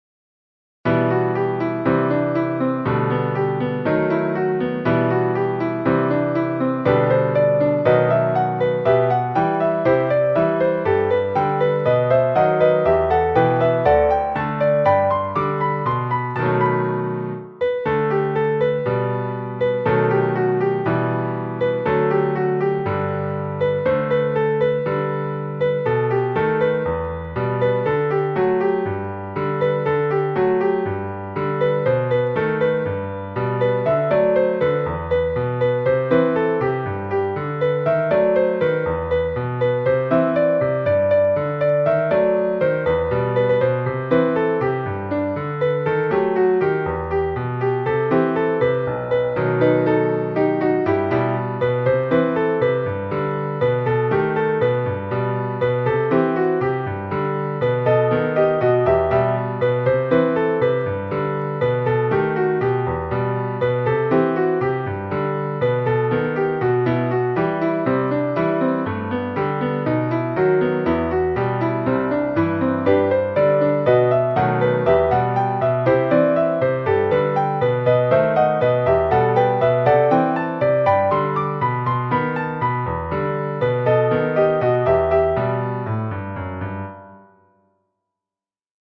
• Теги: Ноты для фортепиано
Ноты для фортепиано.